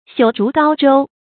朽竹篙舟 注音： ㄒㄧㄨˇ ㄓㄨˊ ㄍㄠ ㄓㄡ 讀音讀法： 意思解釋： 爛竹竿作篙推舟。